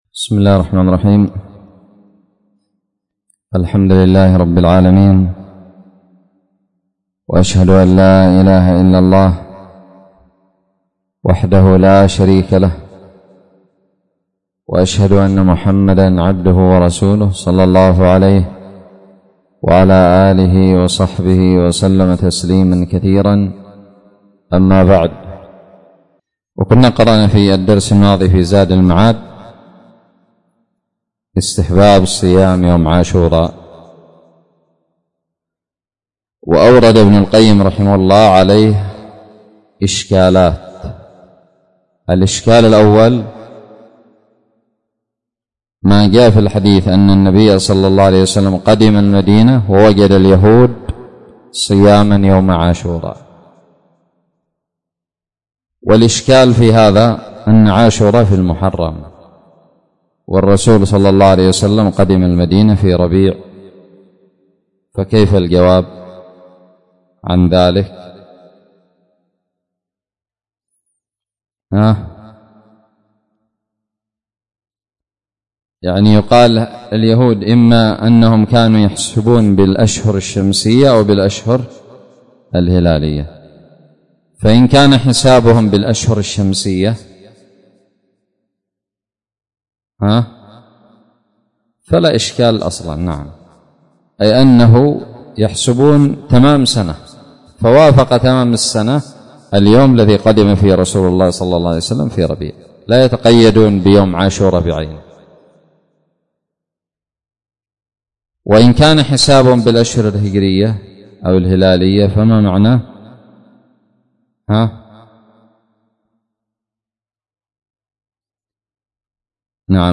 الدرس الرابع عشر من التعليق على فصل هدي النبي صلى الله عليه وسلم في الصوم من زاد المعاد
ألقيت بدار الحديث السلفية للعلوم الشرعية بالضالع